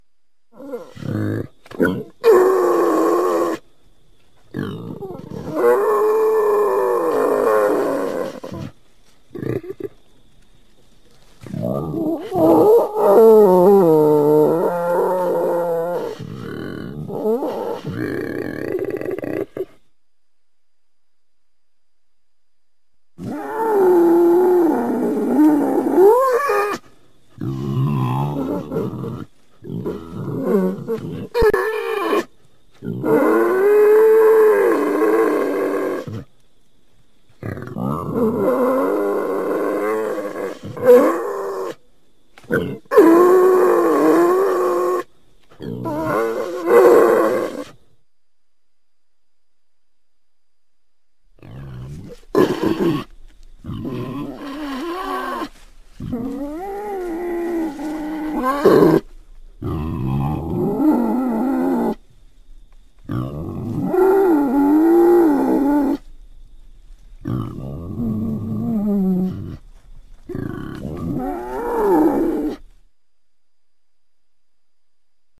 Все записи сделаны в естественной среде обитания животного.
Звуки обычного бородавочника (Phacochoerus africanus)